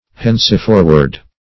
Henceforward \Hence`for"ward\, adv.